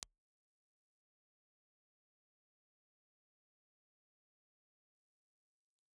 Ribbon
Cardioid
Impulse Response file of the RCA 77A ribbon microphone.